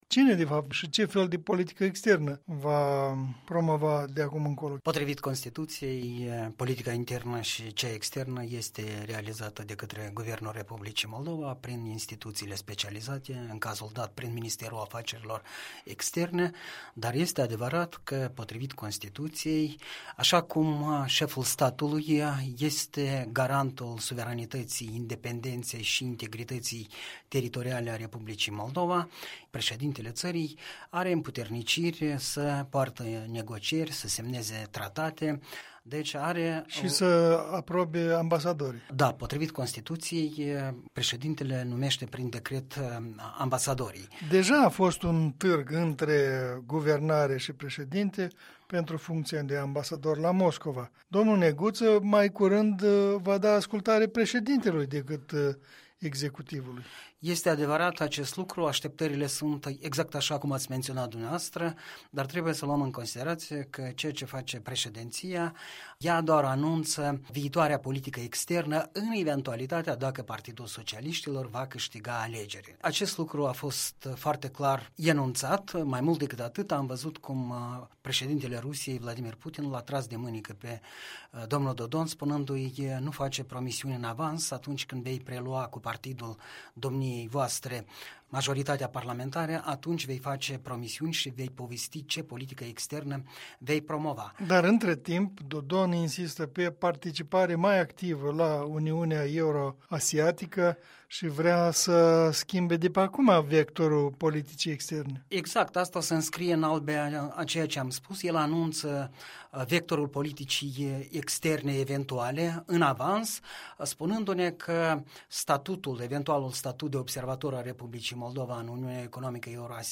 Punct de vedere săptămânal în dialog